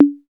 Index of /90_sSampleCDs/Roland - Rhythm Section/DRM_Drum Machine/DRM_Cheese menus